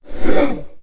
battlemage_attack8.wav